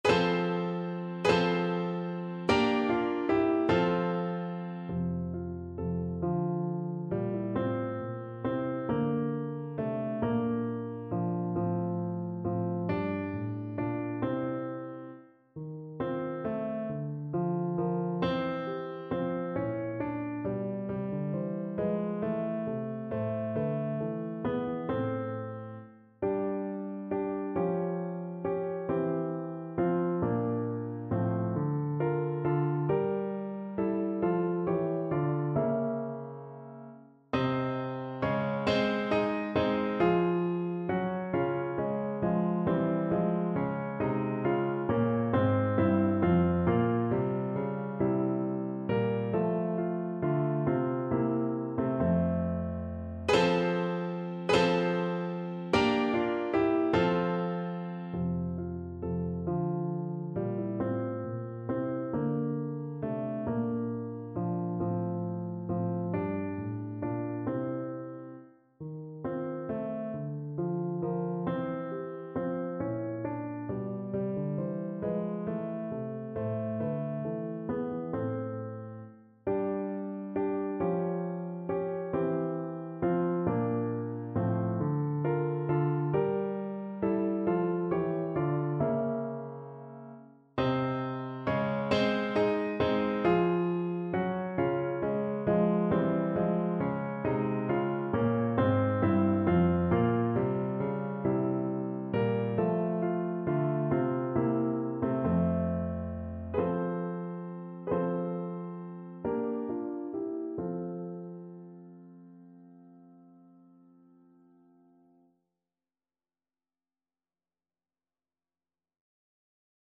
Clarinet
3/8 (View more 3/8 Music)
Allegretto (. = 50)
F major (Sounding Pitch) G major (Clarinet in Bb) (View more F major Music for Clarinet )
Classical (View more Classical Clarinet Music)